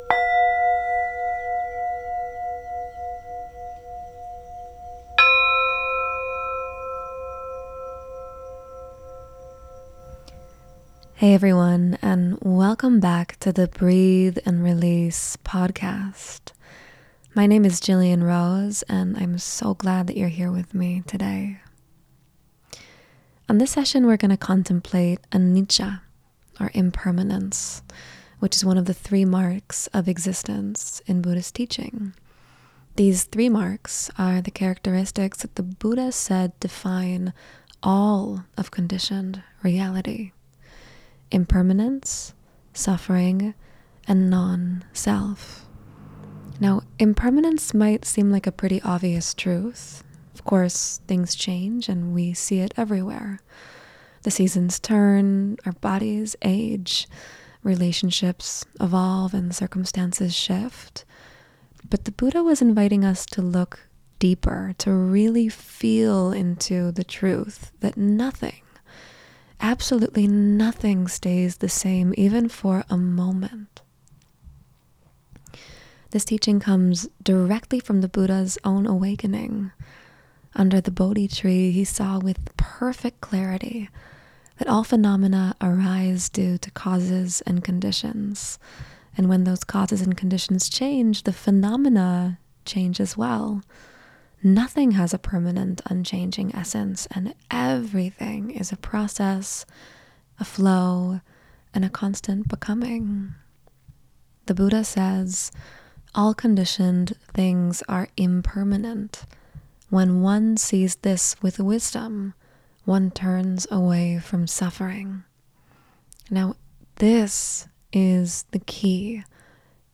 The River of Change: Meditation on Impermanence